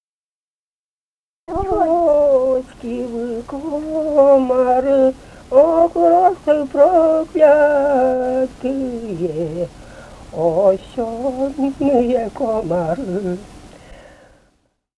Живые голоса прошлого 070а. «Комарочки, вы комары» (лирическая).